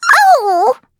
Taily-Vox_Damage_02.wav